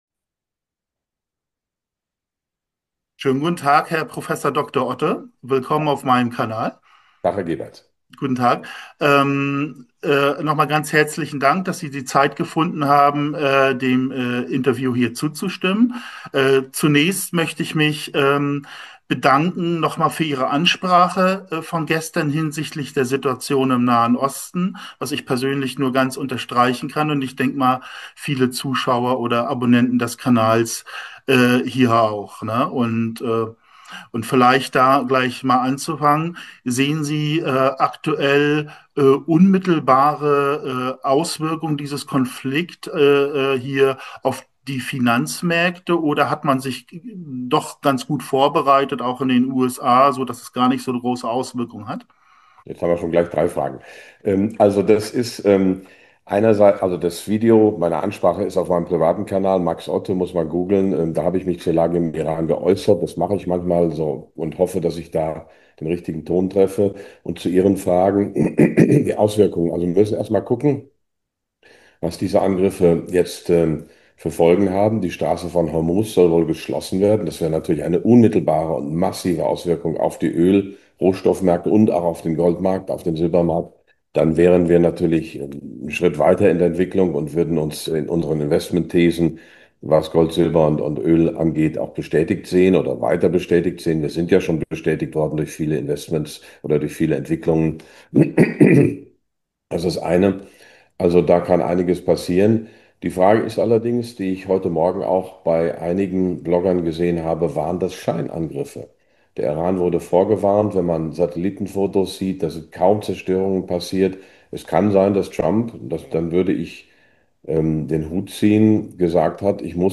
Im exklusiven Interview analysiert Prof. Dr. Max Otte die brandaktuelle Lage. Erfahren Sie, warum Gold und Silber jetzt entscheidend sein könnten, welche einmaligen Chancen sich bei Minenaktien bieten und wie Sie Ihr Vermögen sicher durch die Krise navigieren.